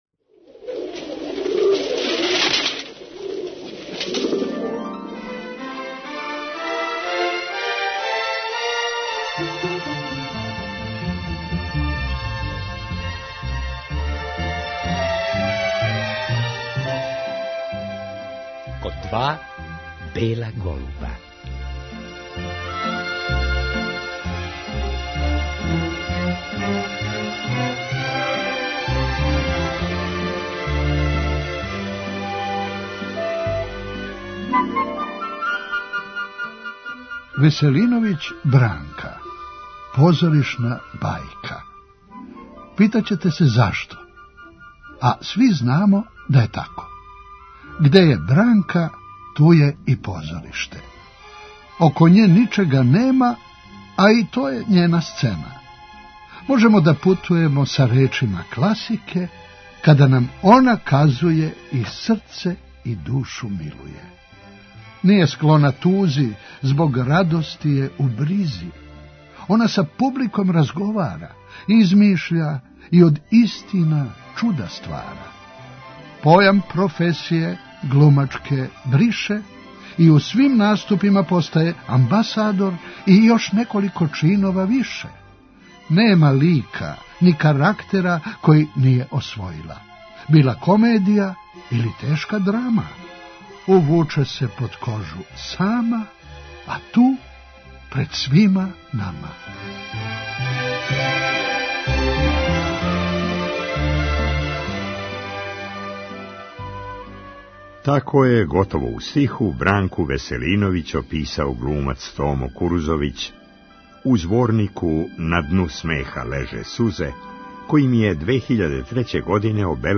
Повод за разговор био је 99. рођендан наше глумице.